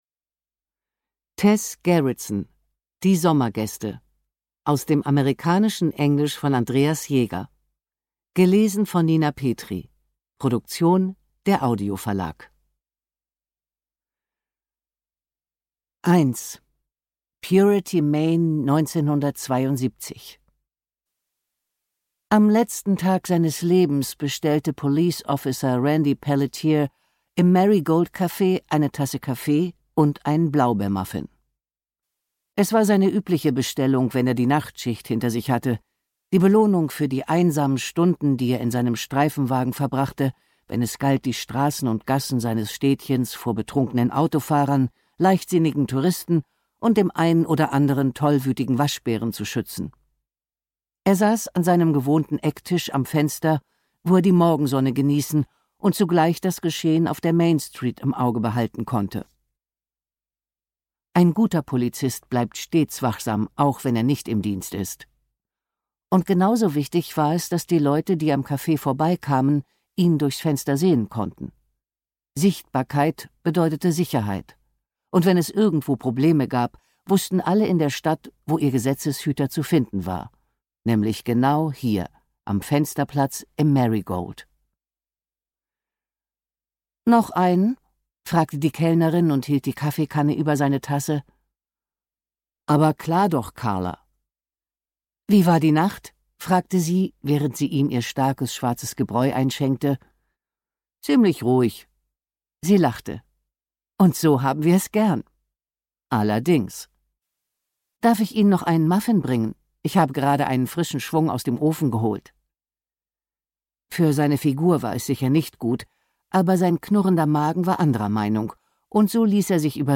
Ungekürzte Lesung mit Nina Petri (1 mp3-CD)
Nina Petri (Sprecher)